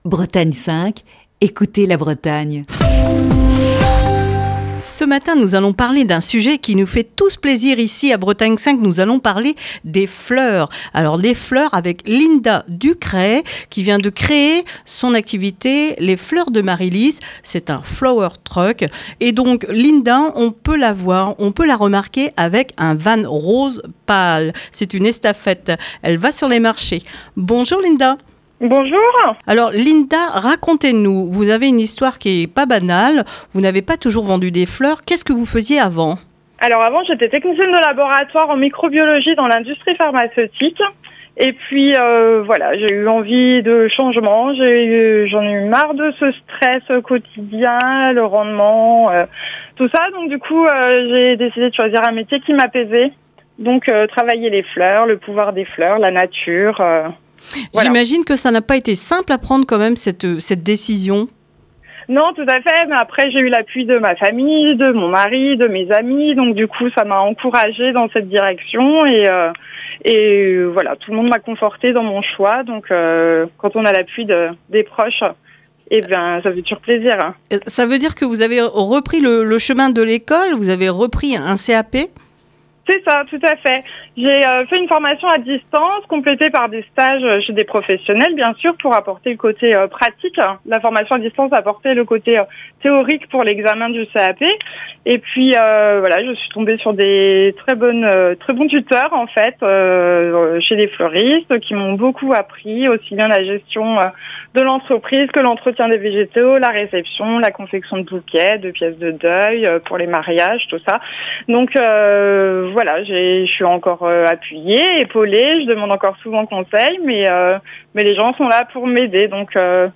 Émission du 9 juin 2021. Dans le coup de fil du matin de ce mercredi